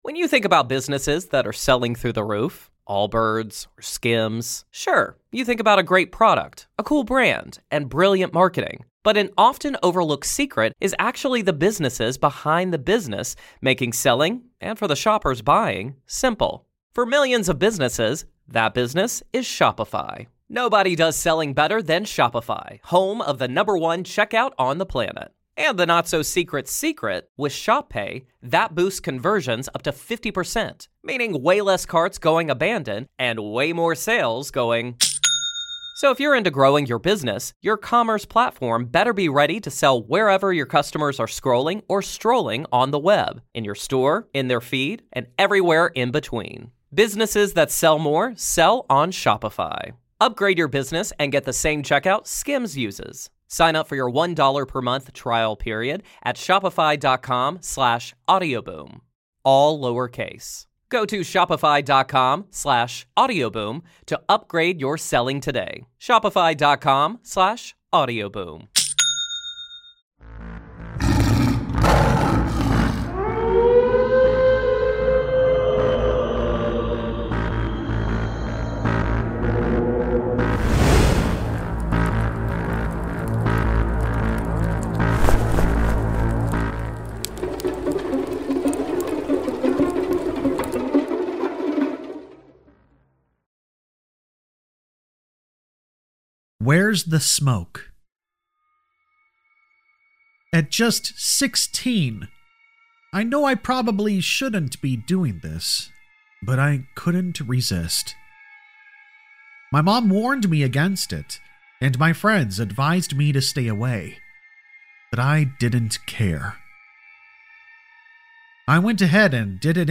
All Stories are read with full permission from the authors:
Sound Effects Credit